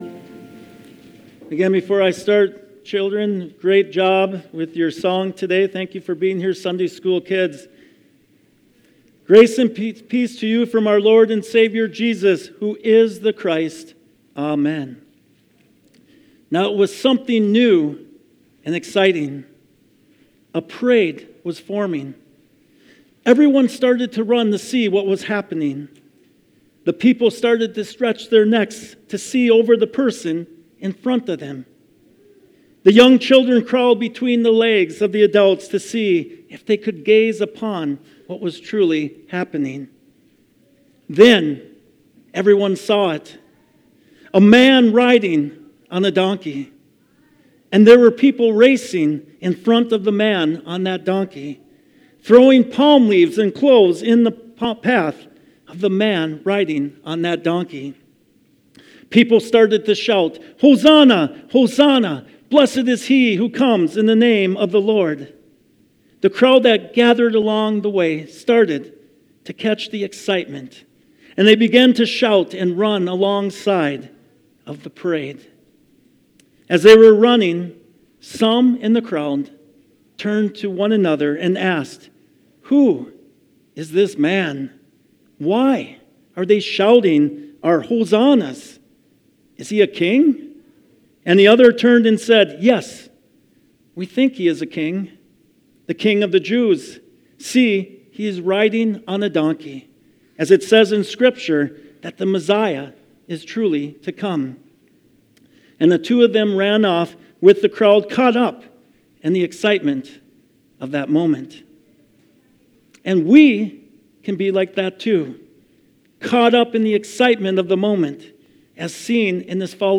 Sermons | Moe Lutheran Church